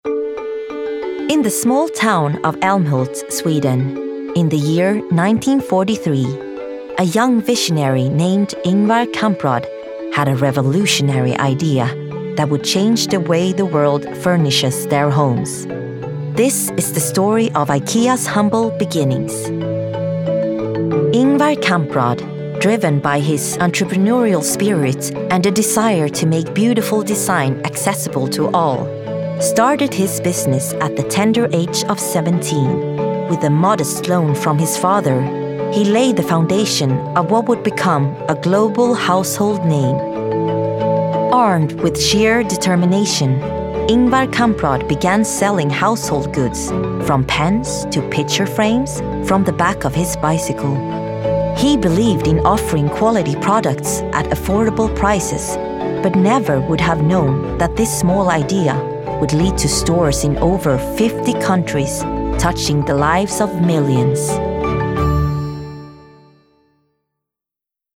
Voice Reel
Narration